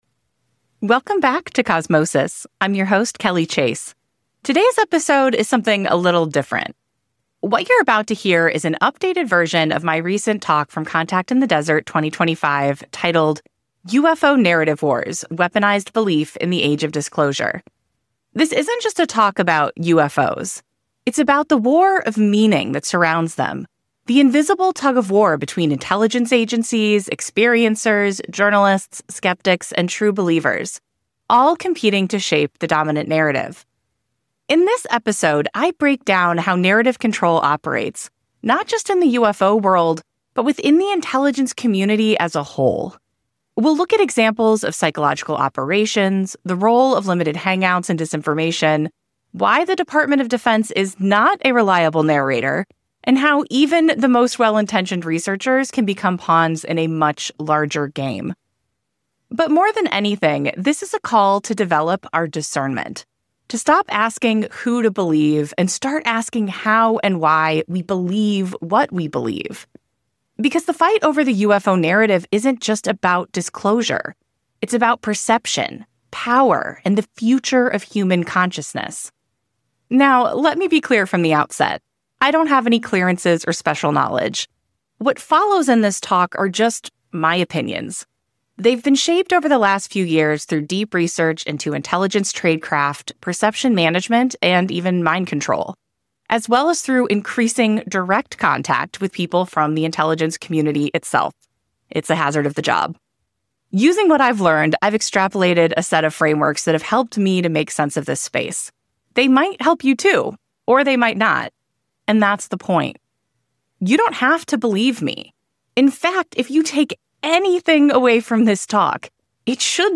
Today's episode is something a little different. 0:07.3 What you're about to hear is an updated version of my recent talk from Contact in the Desert 2025 0:12.4 titled UFO Narrative Wars, weaponized belief in the Age of Disclosure.